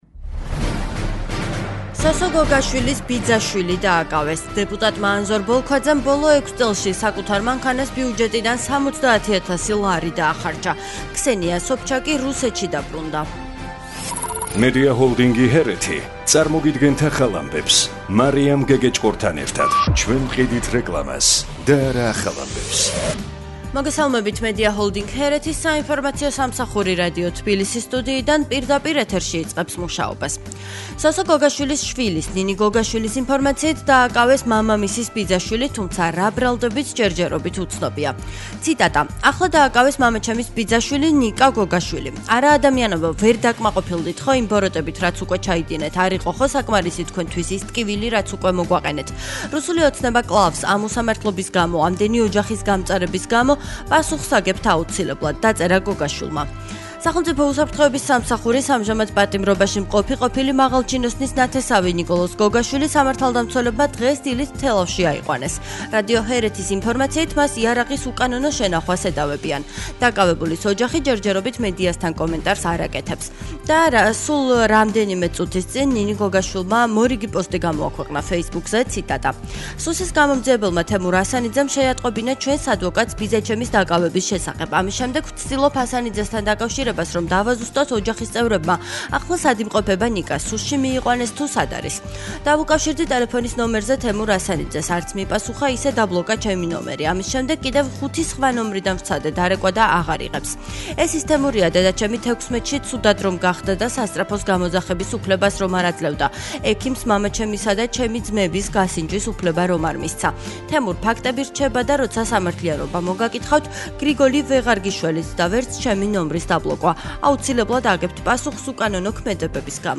ახალი ამბები 14:00 საათზე